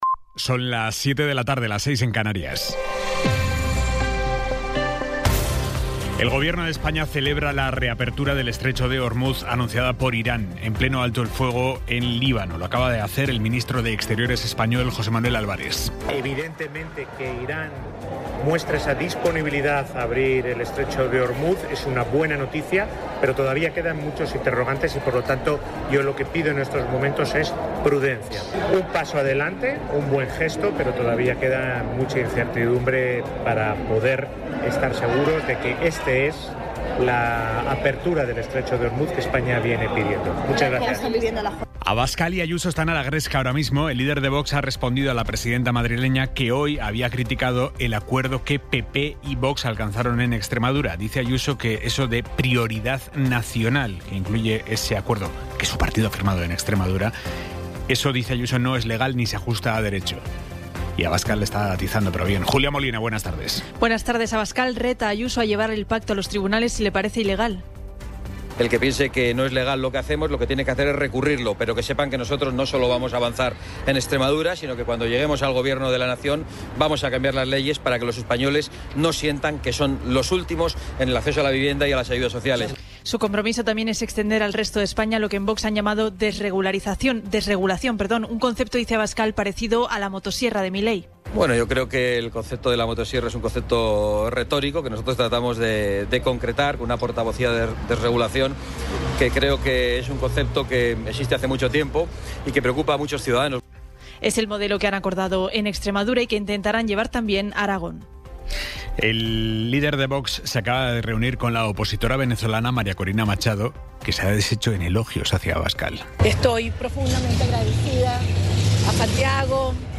Resumen informativo con las noticias más destacadas del 17 de abril de 2026 a las siete de la tarde.